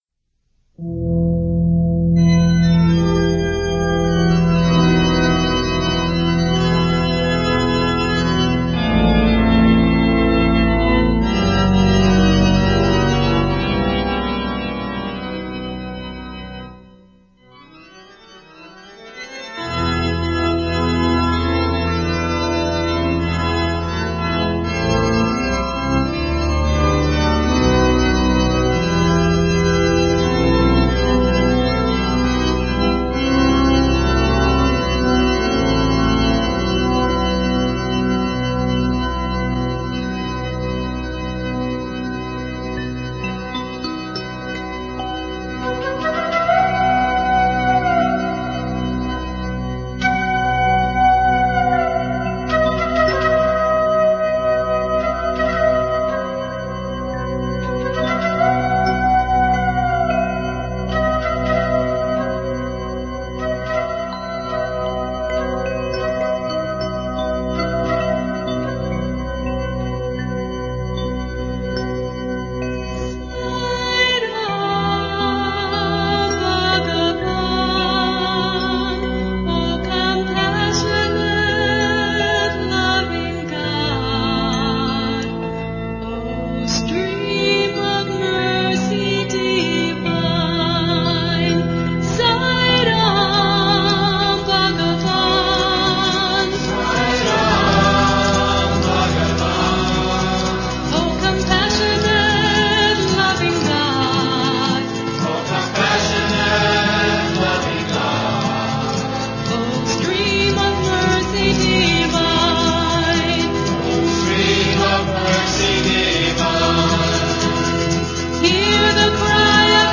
1. Devotional Songs
Pilu 8 Beat  Men - 2 Madhyam  Women - 6 Madhyam
Pilu
8 Beat / Keherwa / Adi
Medium Slow